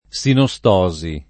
sinostosi [ S ino S t 0@ i ]